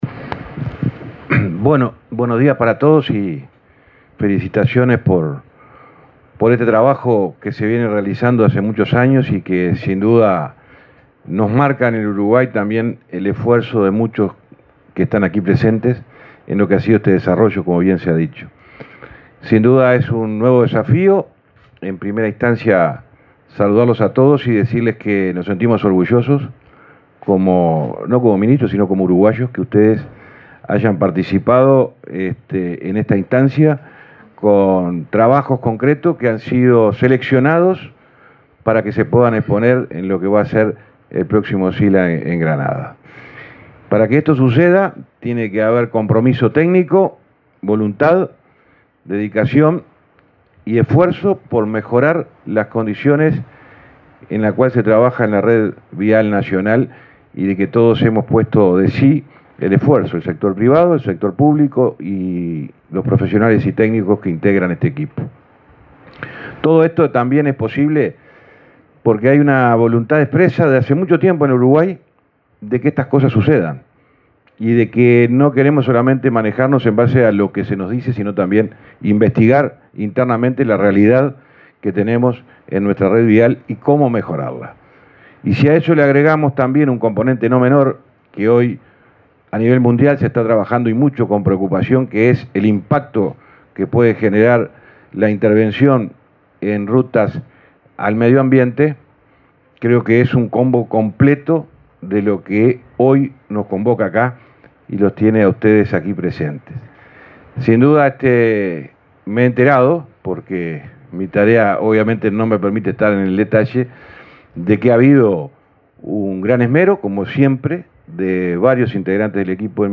Palabras del ministro de Transporte, José Luis Falero
El titular del Ministerio de Transporte y Obras Públicas, José Luis Falero, participó, este jueves 14 en Montevideo, en la exposición de proyectos